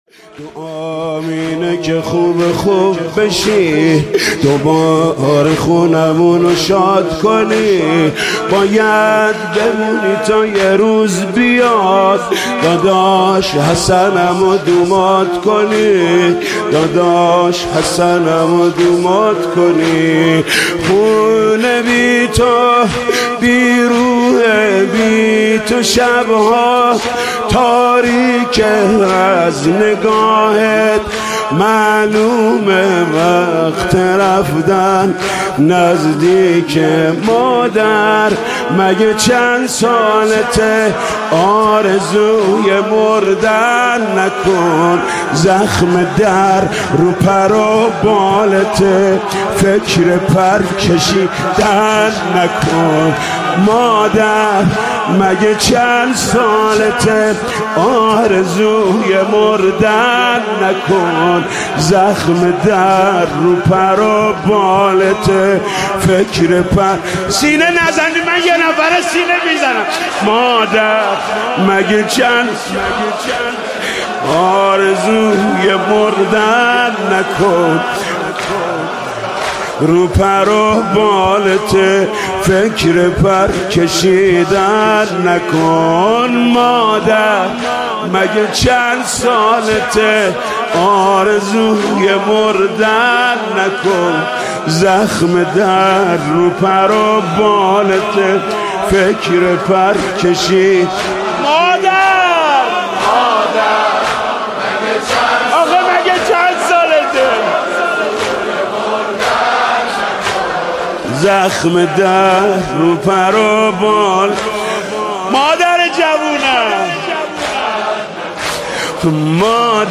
مداحی ایام فاطمیه